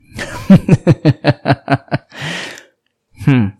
Laughing (male)